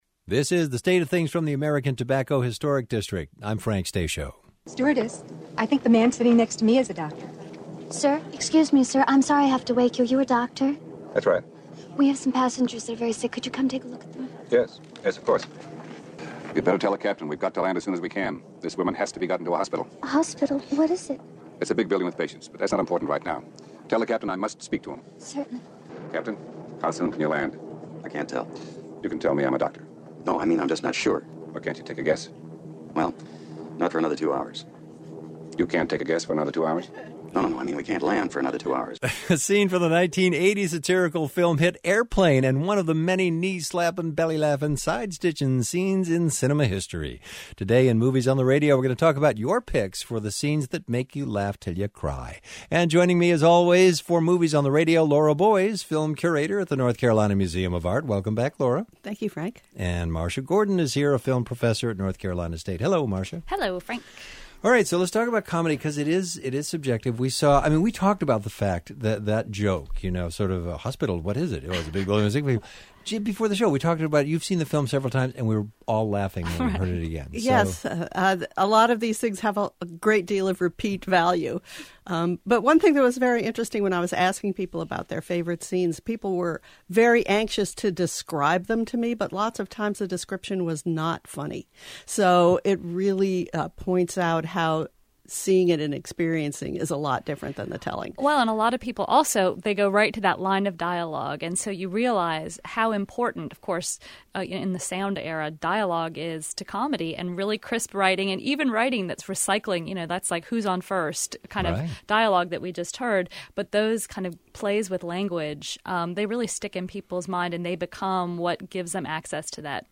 Listeners share their favorite funny scenes from a wide range of movies from “Young Frankenstein” to “Airplane."